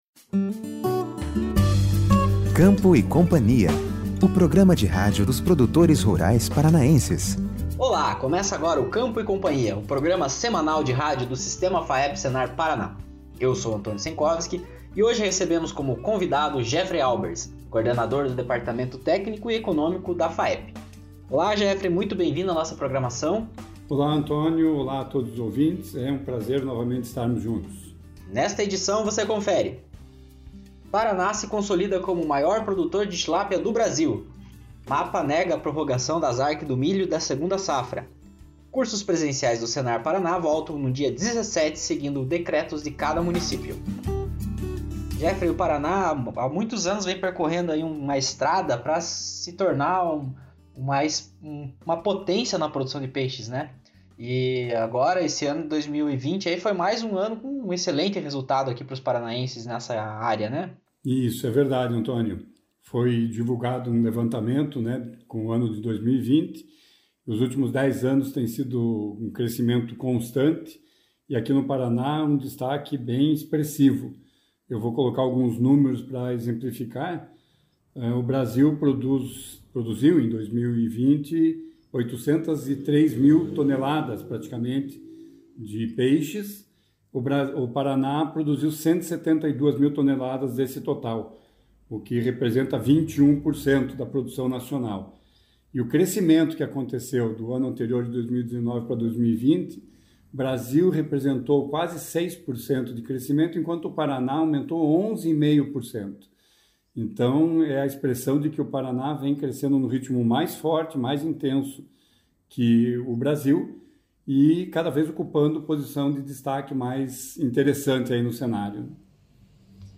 Programa semanal de rádio do Sistema FAEP/SENAR-PR trata dos principais assuntos da agricultura e da pecuária.